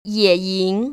[yěyíng] 예잉  ▶